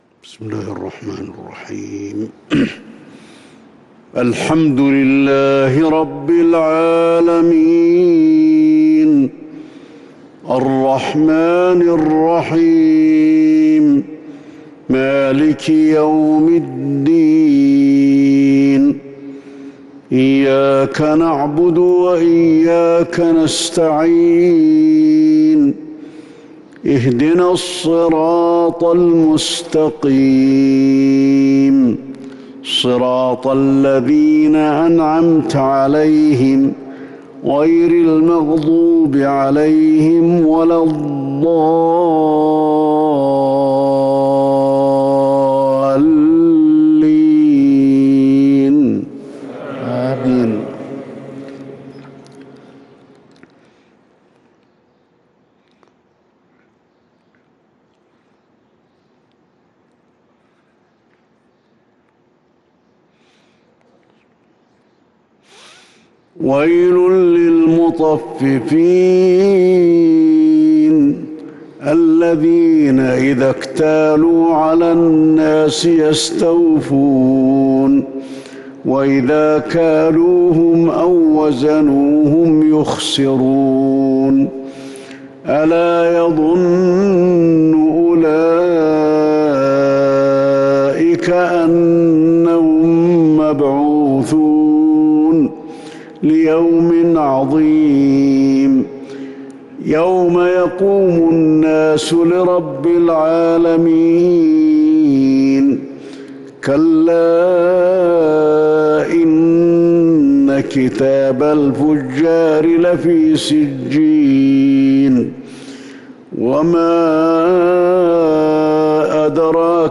صلاة الفجر للقارئ علي الحذيفي 7 ربيع الأول 1443 هـ